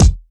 kick 36.wav